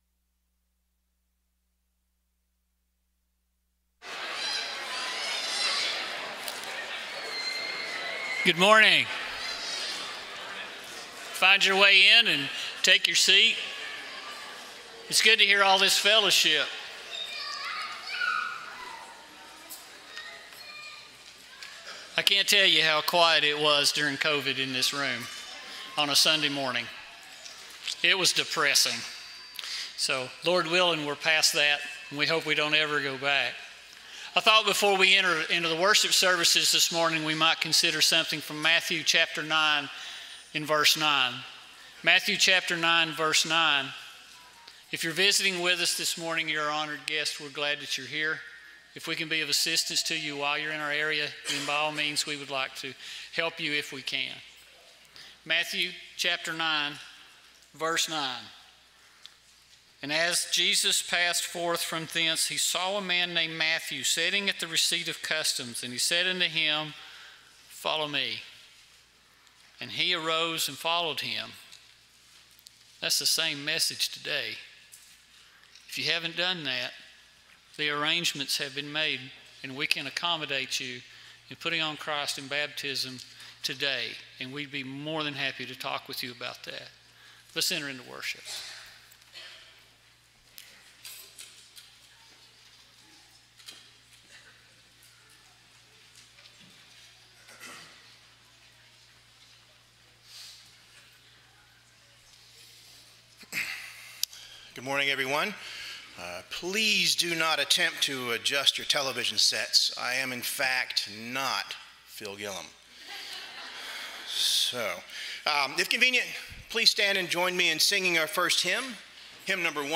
Colossians 3:2, English Standard Version Series: Sunday AM Service